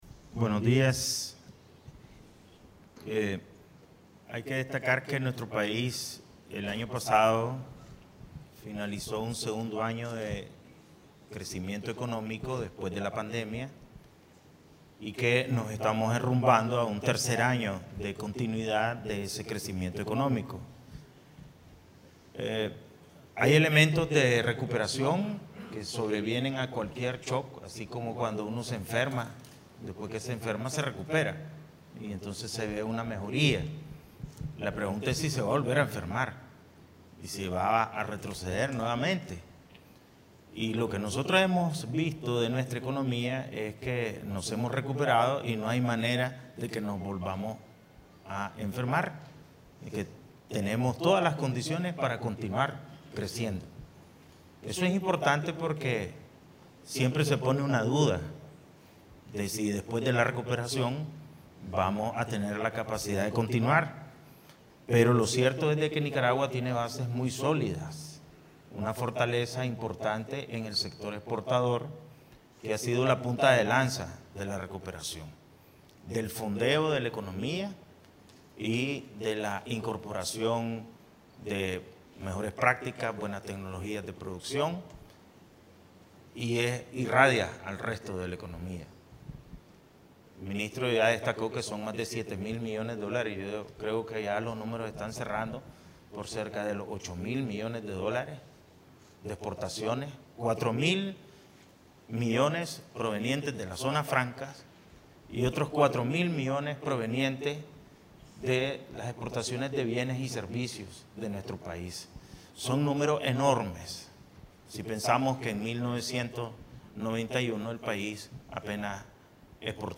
El Presidente del Banco Central de Nicaragua (BCN), Ovidio Reyes R., participó el 18 de enero de 2023 en un encuentro de la Cancillería con Organismos No Gubernamentales (ONG’s) y en esta actividad realizó una exposición sobre la economía nicaragüense durante 2022, destacando la continuidad del crecimiento.